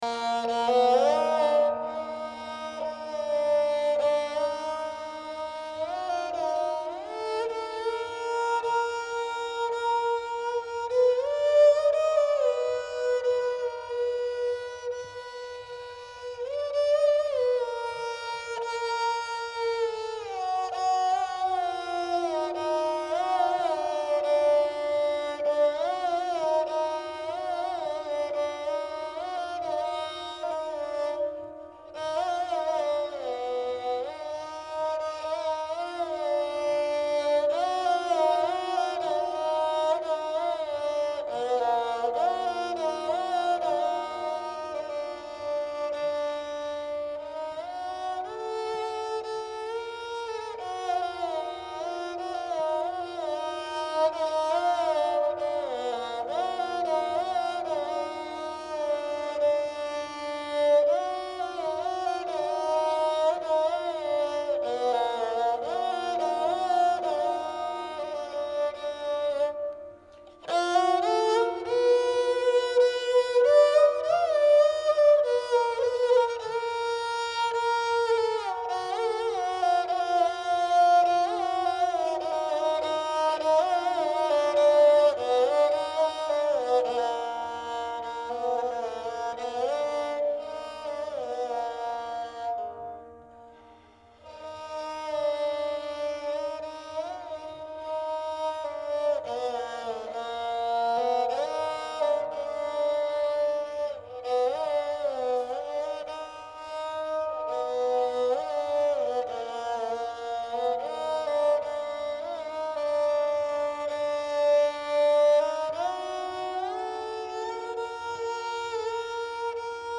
In raag Gauri Rishab and Dhaivat are komal surs.
Dilruba 1: Dilruba 2:
dilruba_gauri_2.mp3